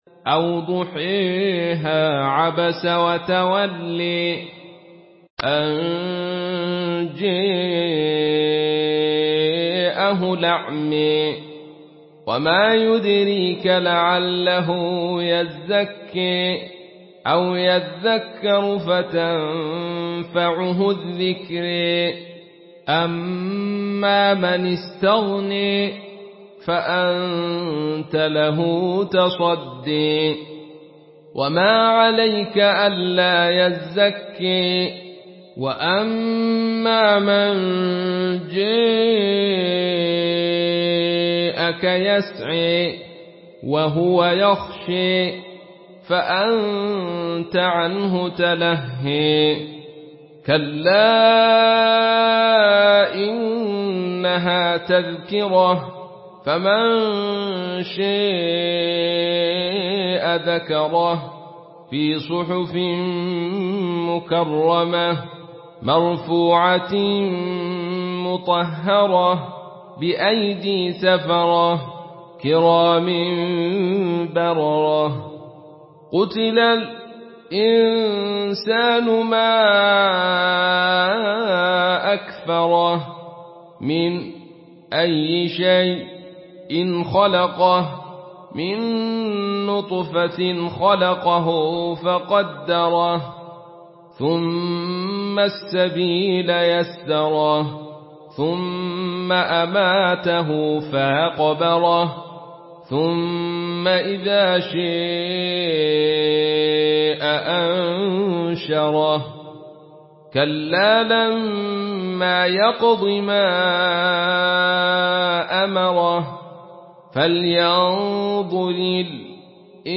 Surah Abasa MP3 in the Voice of Abdul Rashid Sufi in Khalaf Narration
Listen and download the full recitation in MP3 format via direct and fast links in multiple qualities to your mobile phone.
Murattal